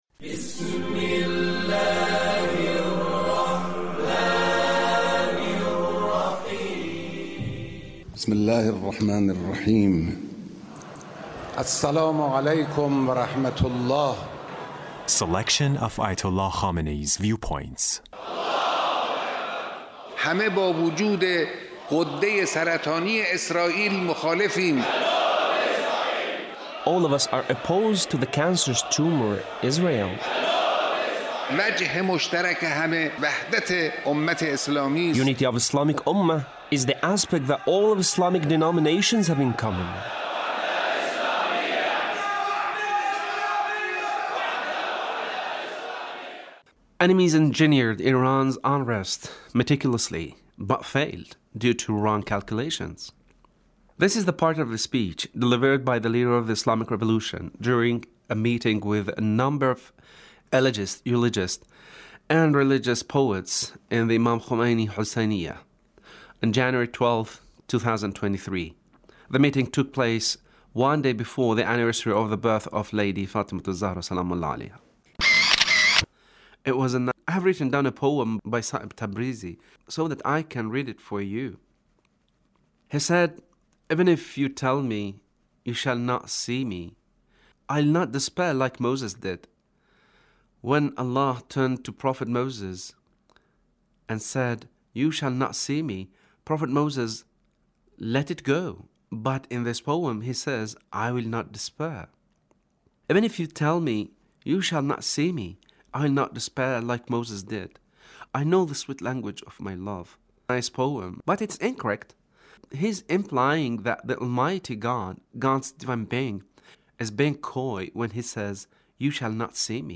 Leader's Speech meeting with Eulogists